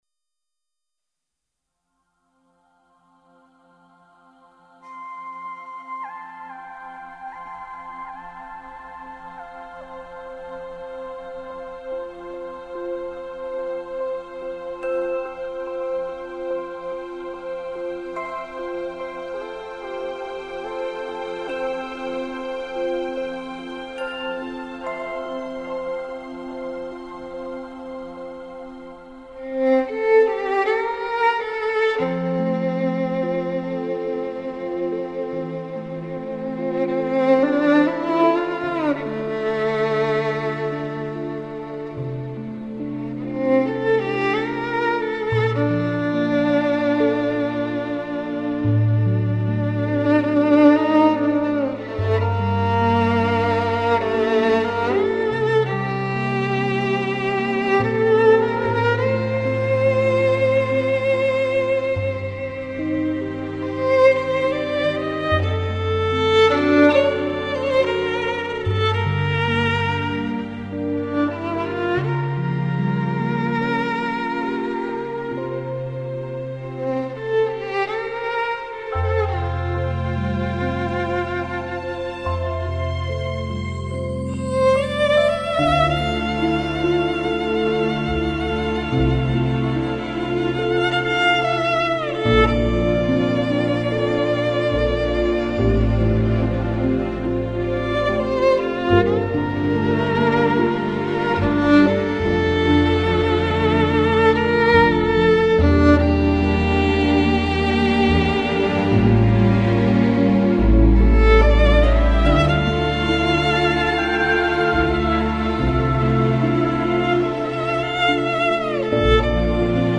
配器方面则以小提琴、钢琴以及人声作为三大支柱，配以自然优雅的木管乐器或者丰富多彩的电子合成器。